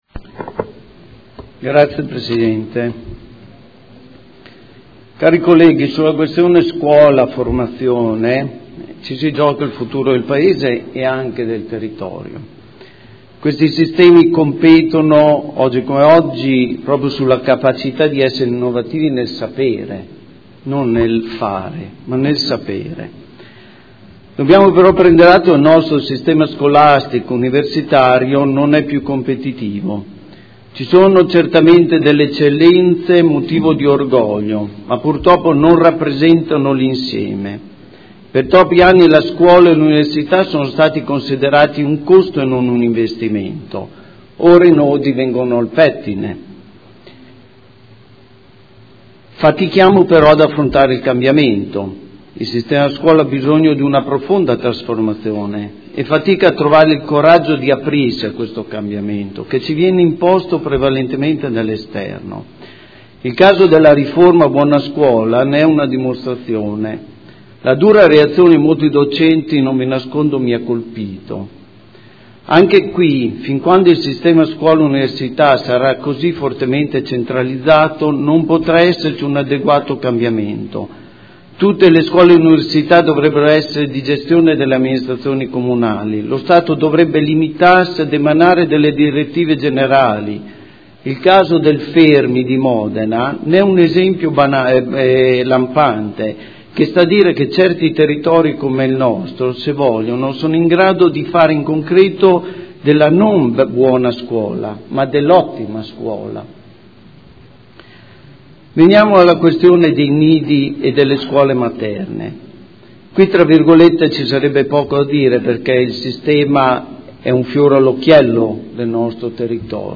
Seduta del 17/09/2015. Dibattito sul progetto "Educare Insieme", piano della buona scuola a Modena